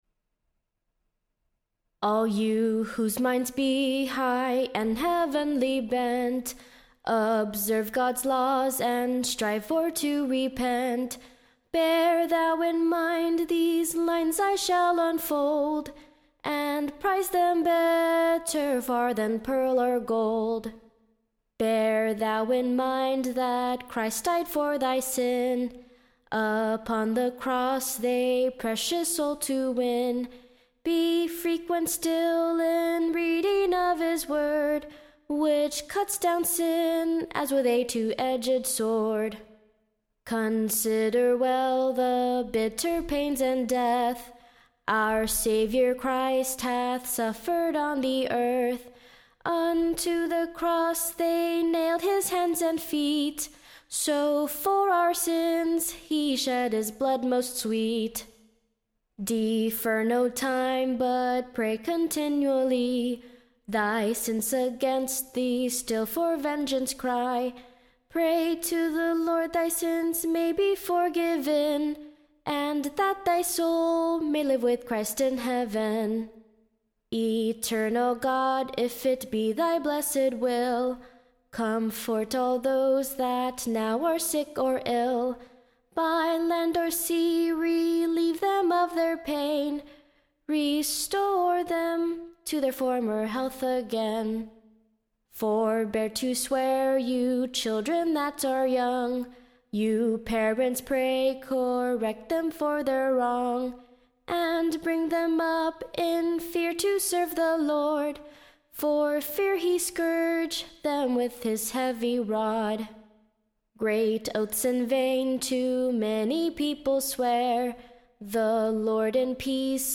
Recording Information Ballad Title A Lesson for all True CHRISTIANS.
Tune Imprint Tune of, The Letter for a Christian Family. Standard Tune Title Fortune my Foe Media Listen 00 : 00 | 5 : 48 Download r2.271.mp3 (Right click, Save As)